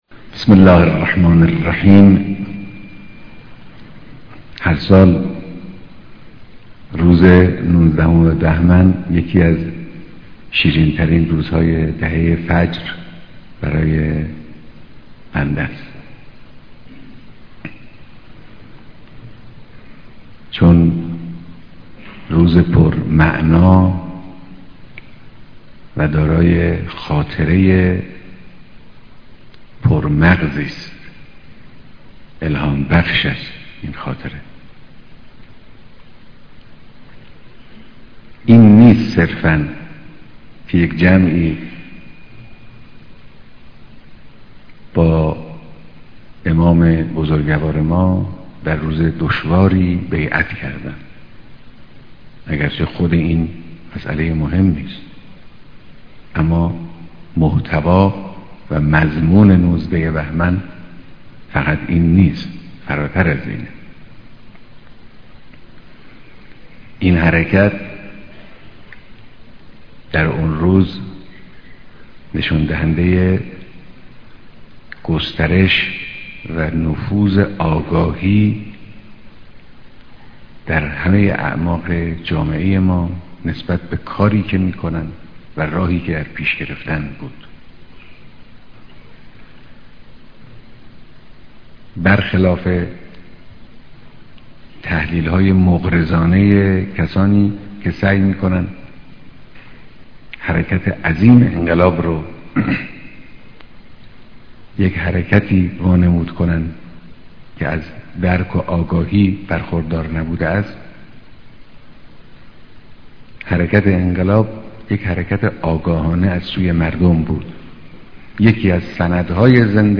بيانات در ديدار پرسنل نيروى هوايى